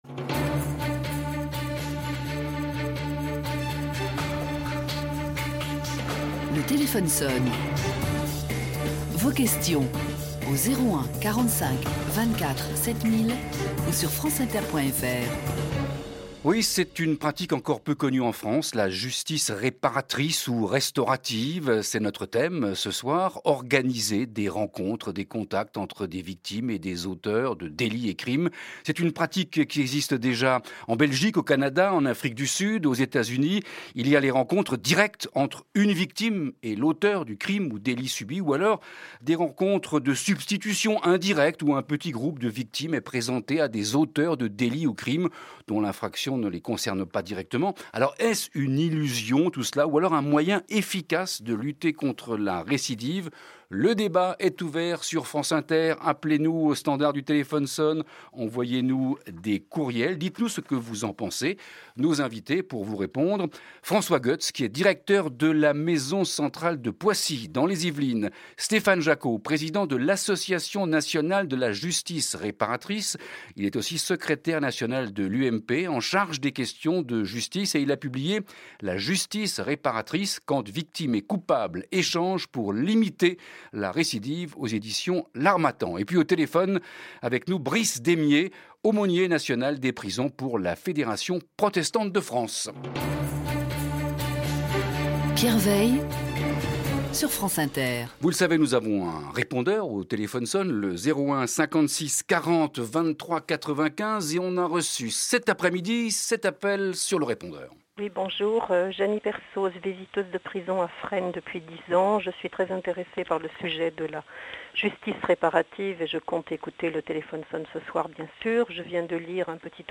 Les invités